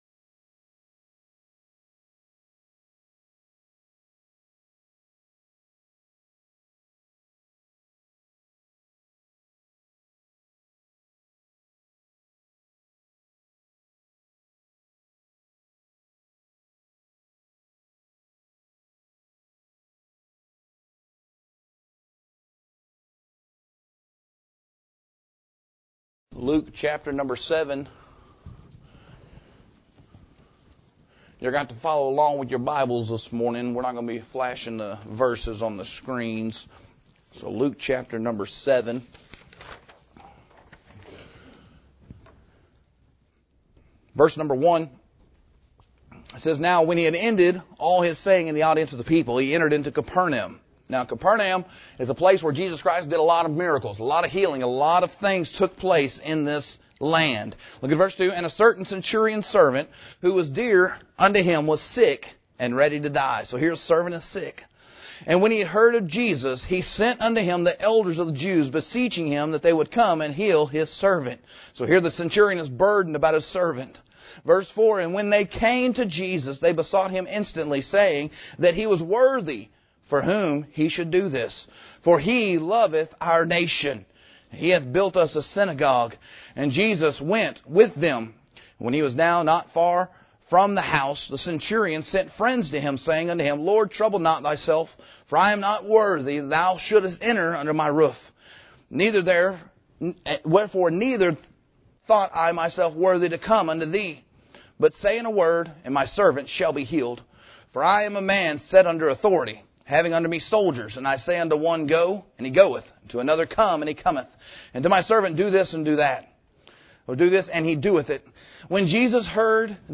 preaches from Luke 7 on what the Christian’s responsibility should be to their nation.